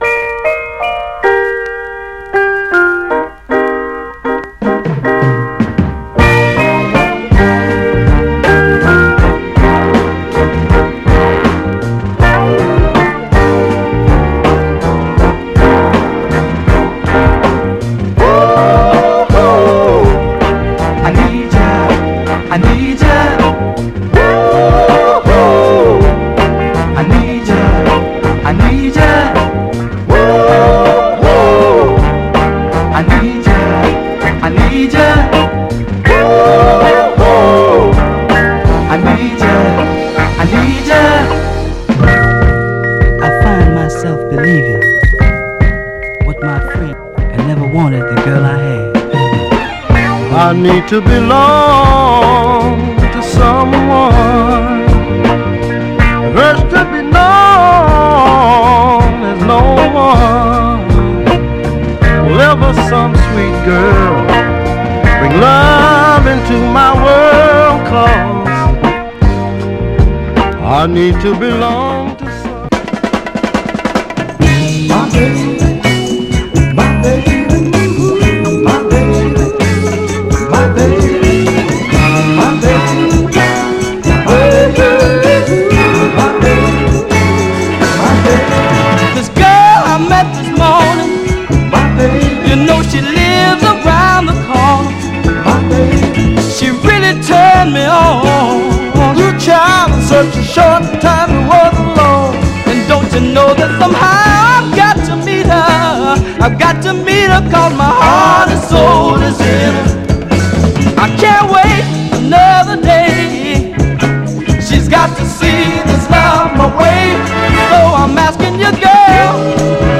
盤はエッジ中心に細かいスレ箇所、B面序盤に小キズ箇所ありますが、グロスが残っており両面プレイ良好です。
※試聴音源は実際にお送りする商品から録音したものです※